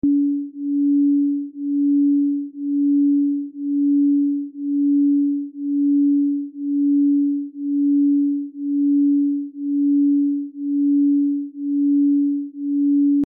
285 Hz Relax, Release Sound Effects Free Download